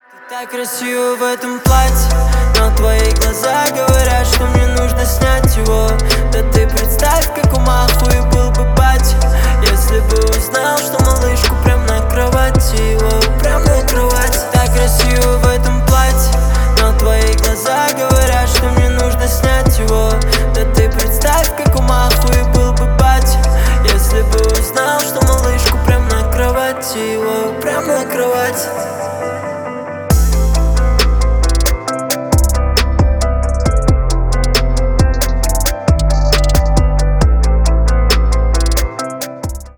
Рэп и Хип Хоп
грустные